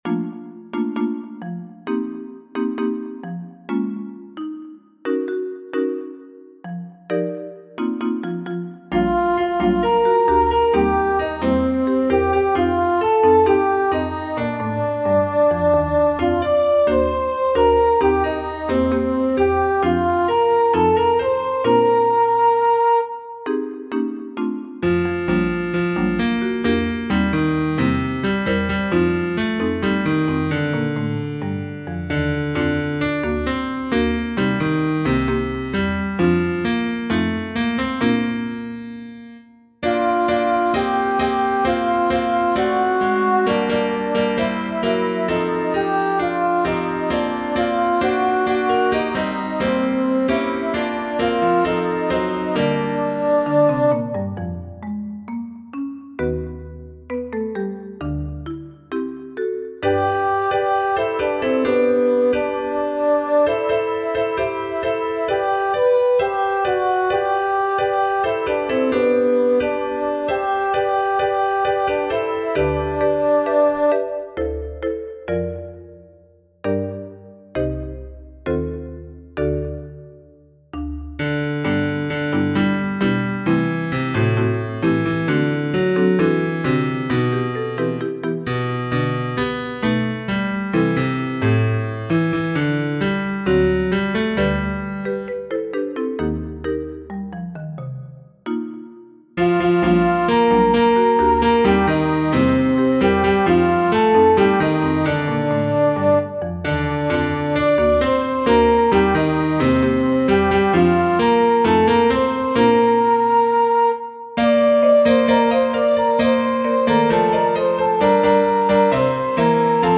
The recordings begin at letter A. The choir is a piano, the featured voice is a horn. The accompaniment is a marimba.
marysboychild-alto2.mp3